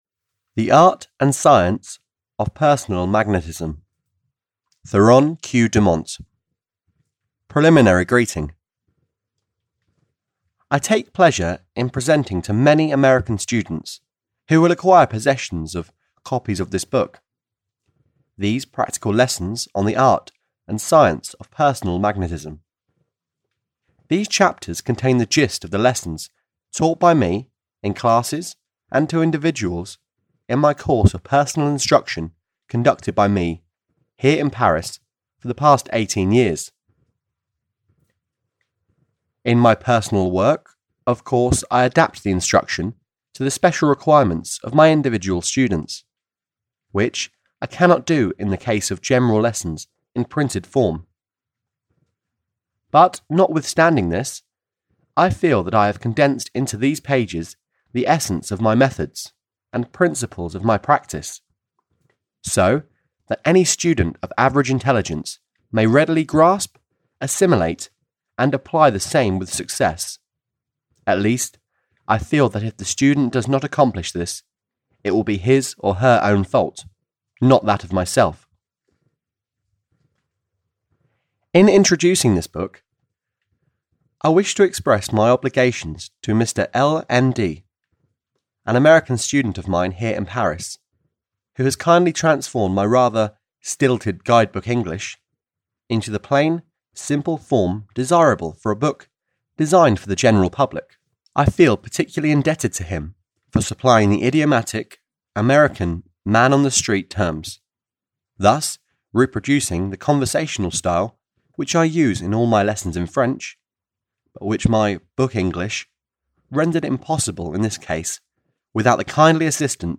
Audio knihaThe Art and Science of Personal Magnetism (EN)
Ukázka z knihy